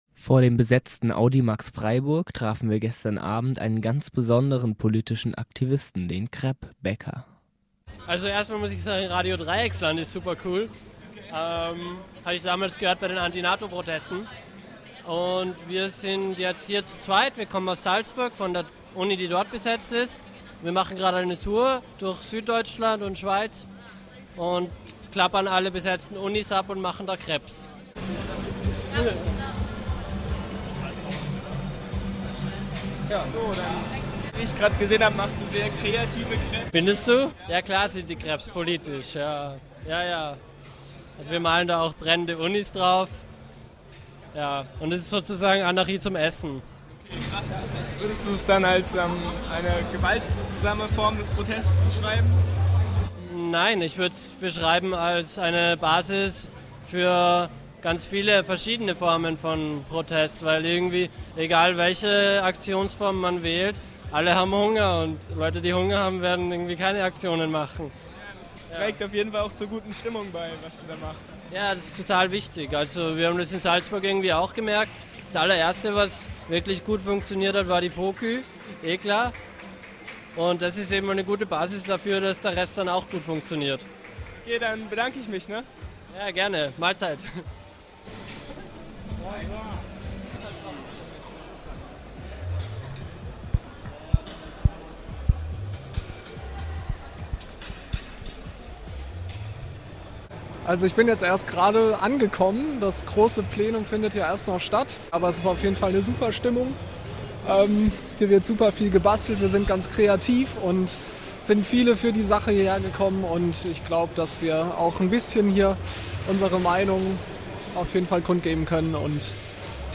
Stimmungsbild aus dem besetzten Audimax